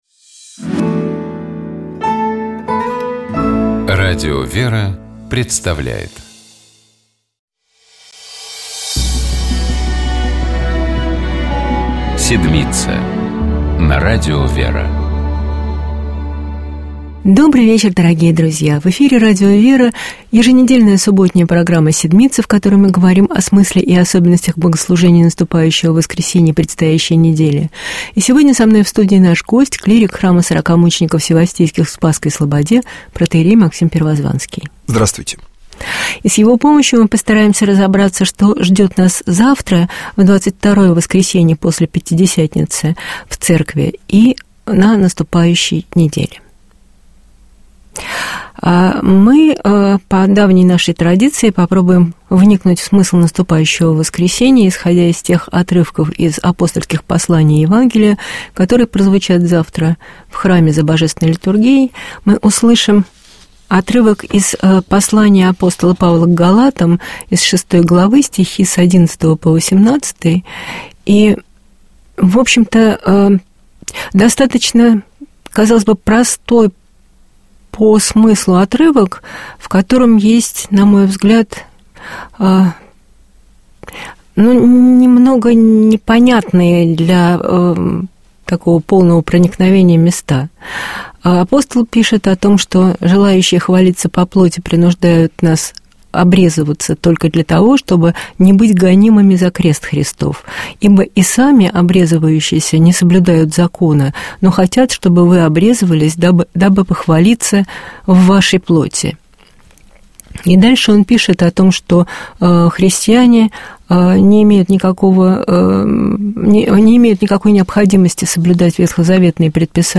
Комментирует священник